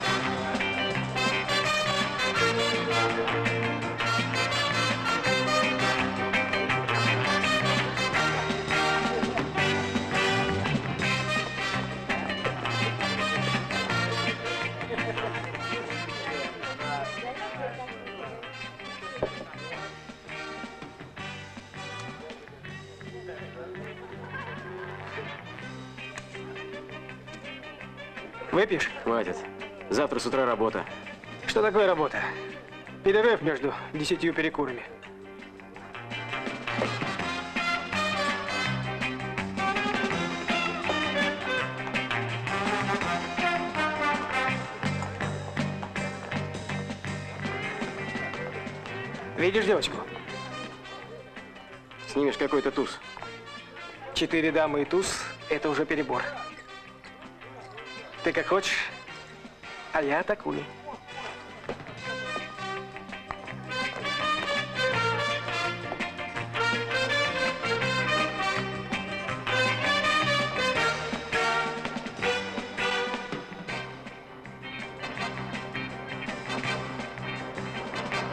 по небольшому отрывку из к.ф